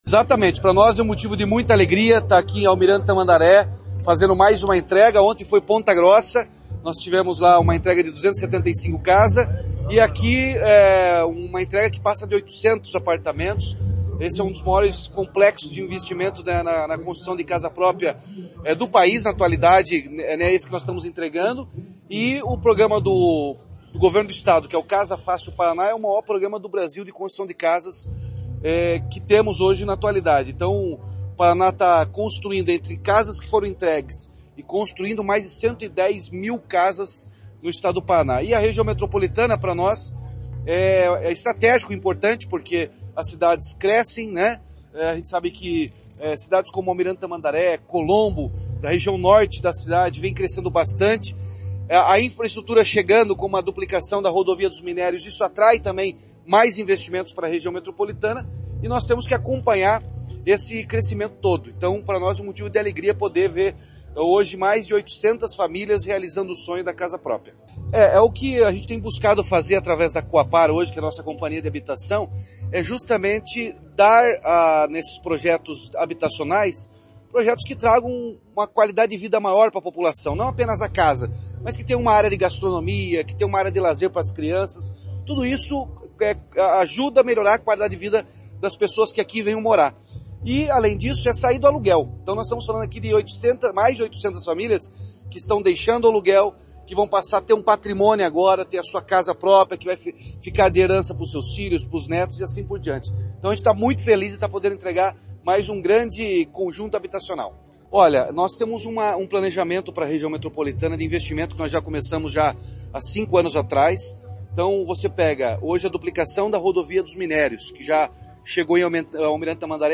Sonora do governador Ratinho Junior sobre a inauguração de um condomínio com apoio do Estado em Almirante Tamandaré